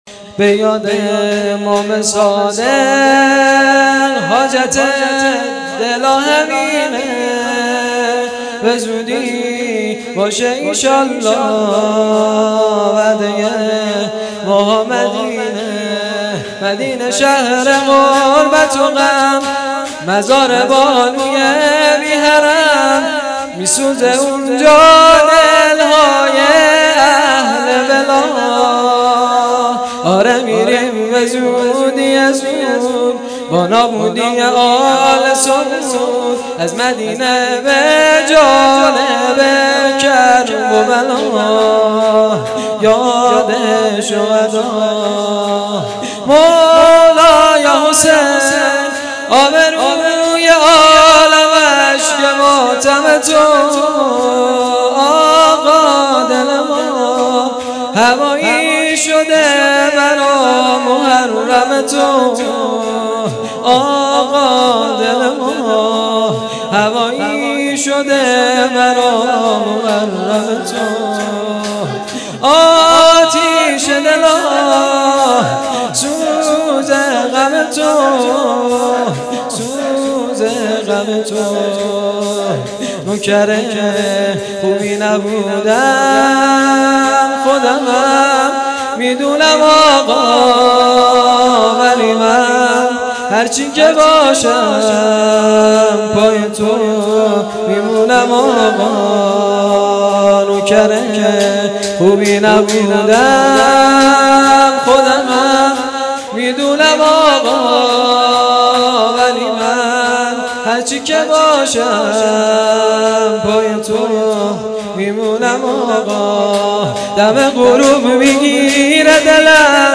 شهادت امام صادق علیه السلام-شب اول